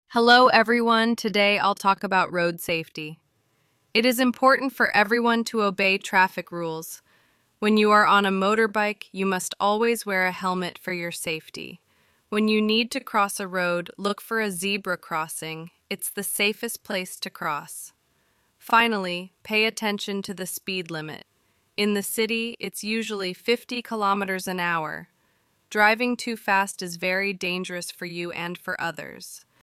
Part 2: You will hear someone giving road safety advice.